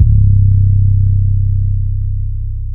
Jumpman 808.wav